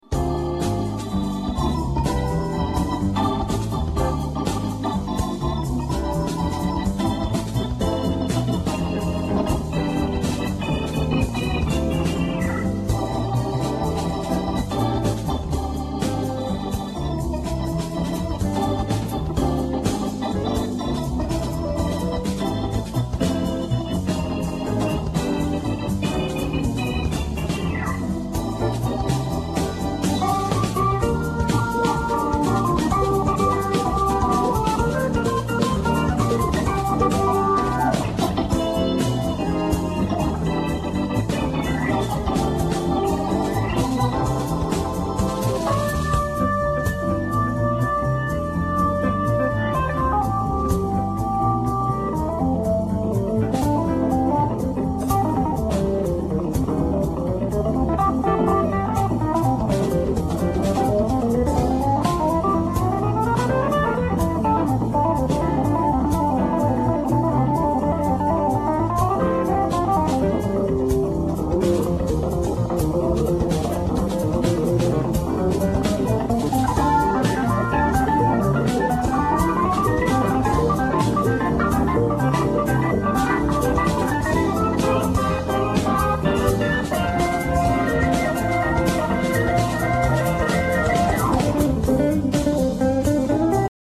Pour être mécanique, c'est mécanique.
Virtuose mais sans âme.
De la belle musique de supermarché comme je dis !!